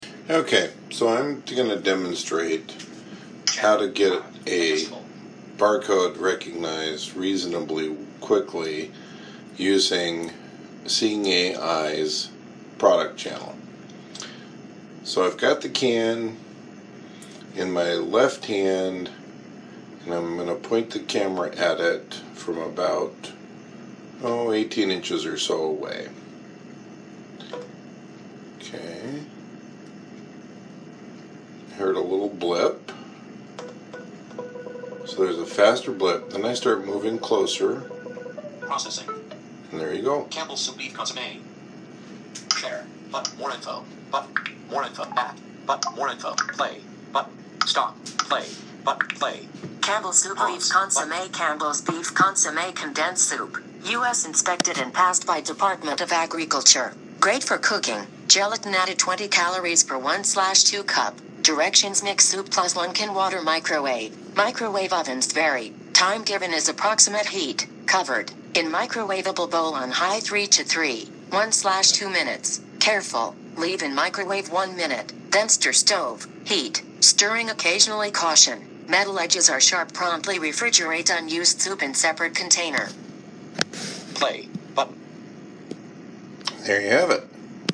Below is a link to a short recording demonstrating the product channel in the Seeing AI app.
Bar Codes are usually near the label seam on a can or bottle, on the bottom of boxes, along the seam of a bag, etc. When you hear the first beep on your device, turn the item slowly until you hear fast beeps.
SeeingAIProductChannelDemo.mp3